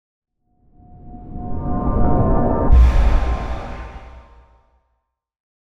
divination-magic-sign-circle-intro.ogg